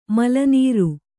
♪ mala nīru